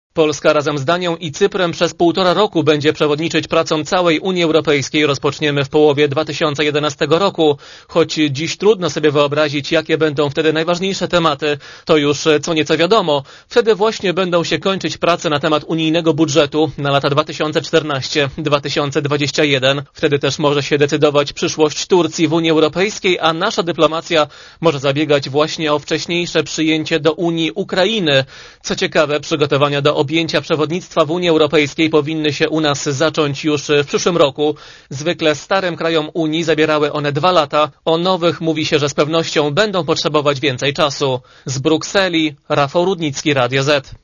Korespondencja z Brukseli